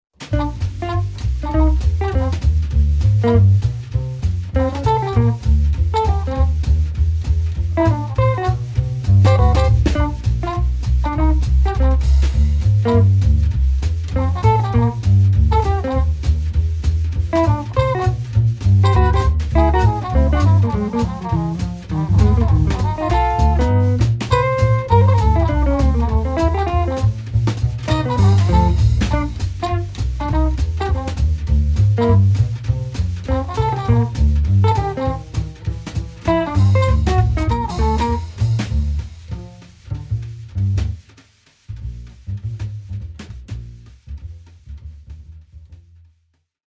guitar
sax, flute
bass
drums